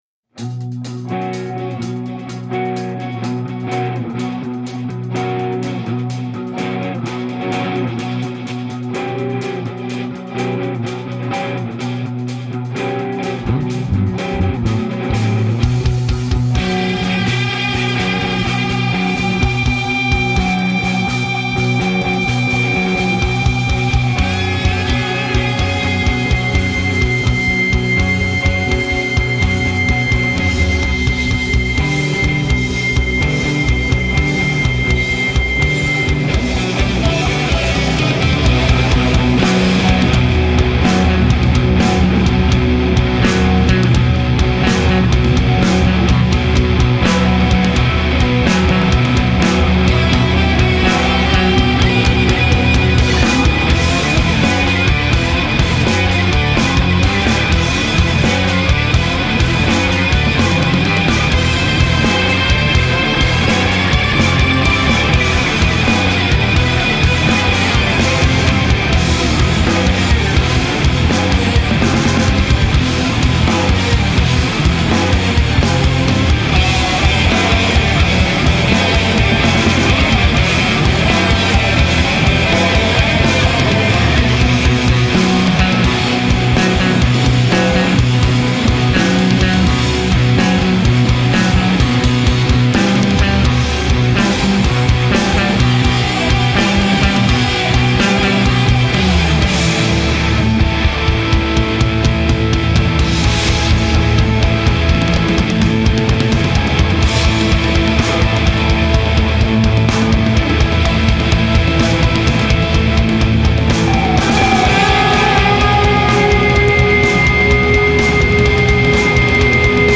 Modern Instrumental Post Rock Metal!!
GUITAR
BASS
DRUMS